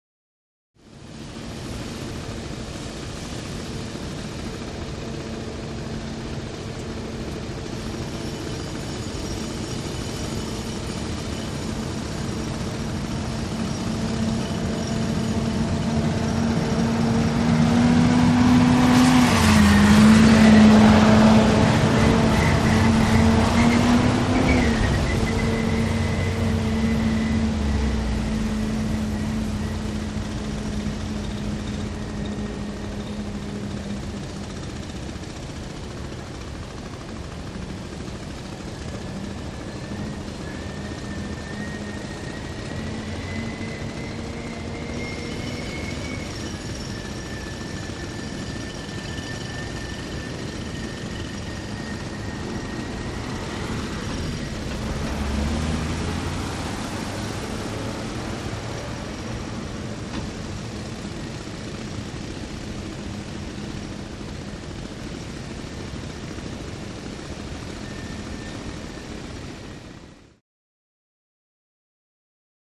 Dam Atmosphere; Exterior Dam During Construction. Long Shot Pneumatic Drills With Heavy Truck Close Drive Bye, Followed By A Smaller Vehicle.